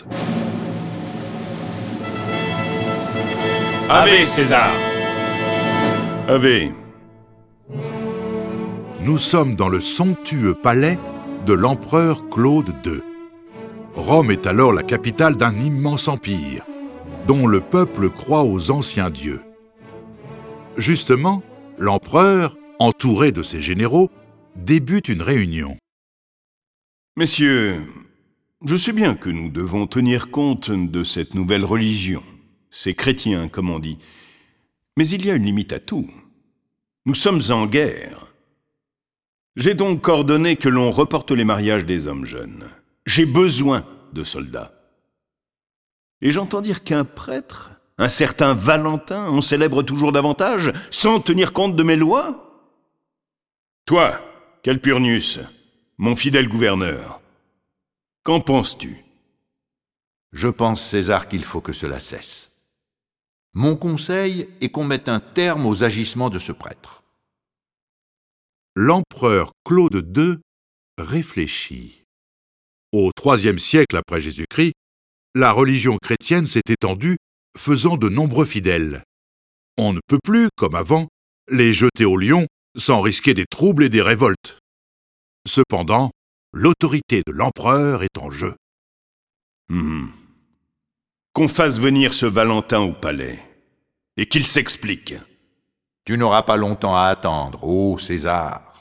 Diffusion distribution ebook et livre audio - Catalogue livres numériques
Découvrez la vie de ce jeune prêtre qui mariait en secrets de jeunes fiancés, qui convertit de nombreux chefs romains et accomplit de nombreux miracles. Cette version sonore de la vie de saint Valentin est animée par dix voix et accompagnée de plus de trente morceaux de musique classique.